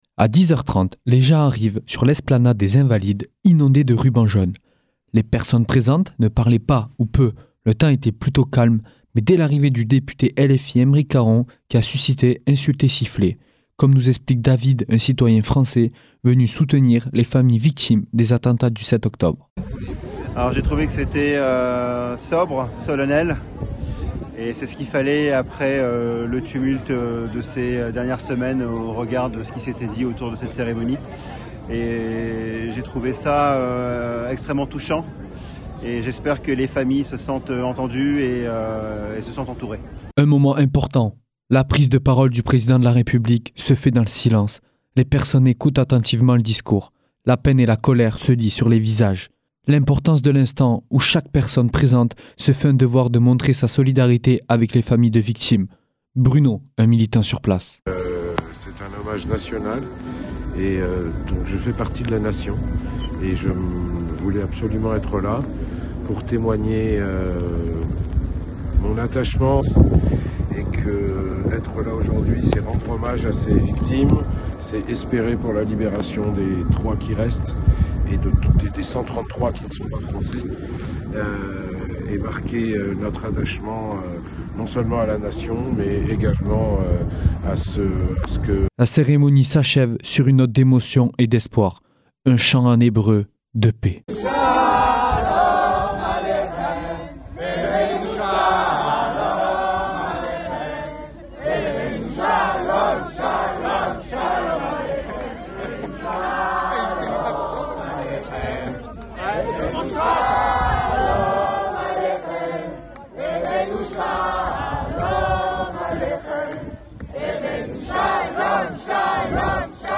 Dehors, un millier d'anonymes ont bravé la pluie pour rendre hommage aux victimes du Hamas